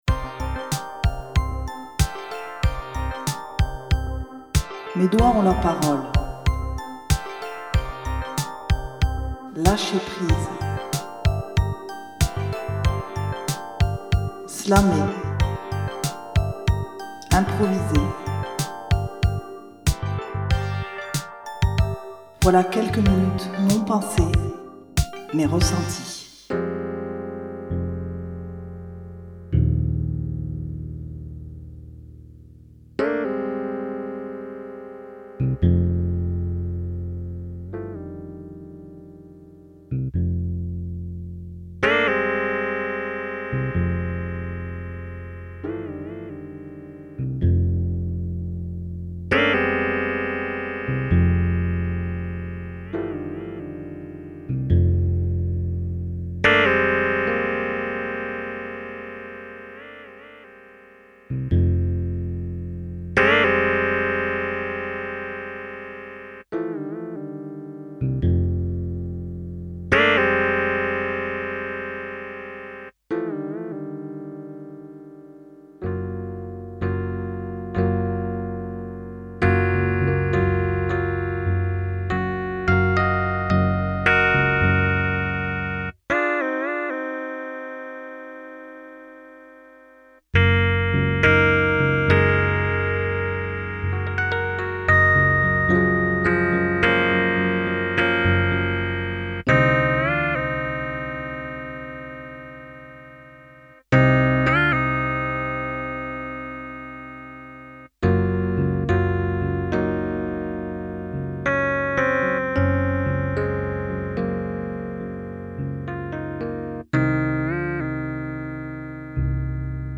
une impro au clavier toujours aussi improbable et relaxante...